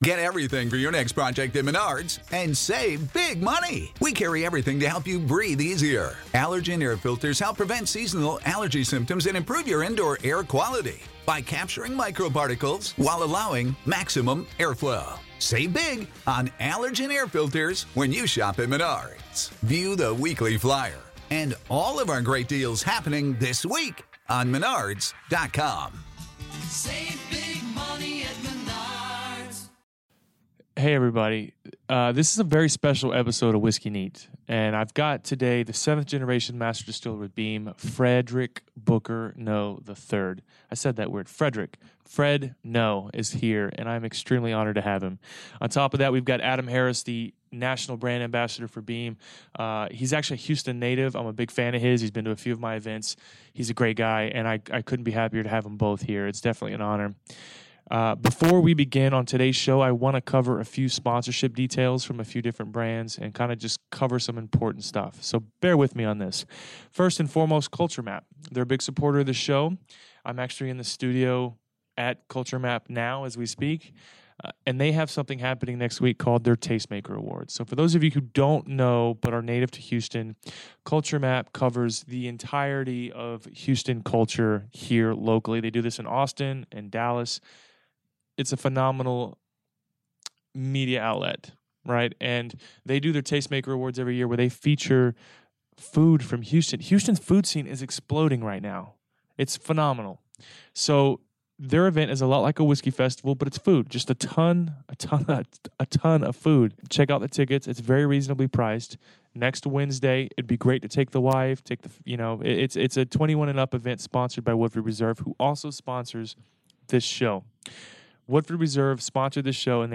Apologies for the audio glitches early on. . . It gets better as the episode progresses and we changed mics for next weeks episode.